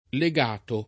vai all'elenco alfabetico delle voci ingrandisci il carattere 100% rimpicciolisci il carattere stampa invia tramite posta elettronica codividi su Facebook legato [ le g# to ] part. pass. di legare , agg., s. m. (mus.